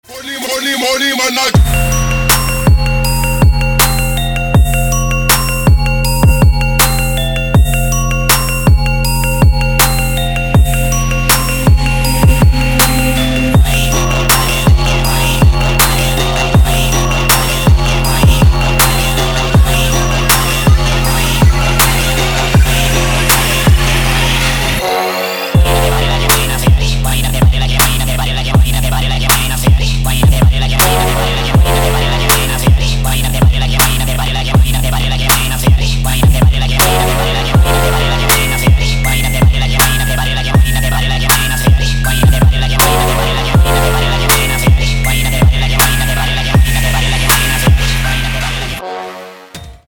• Качество: 160, Stereo
Trap
Bass
трэп